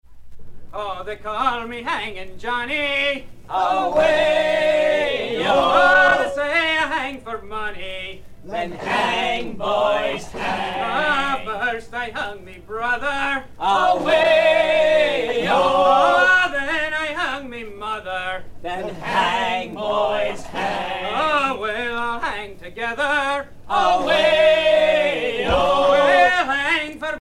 maritimes
Sea chanteys and sailor songs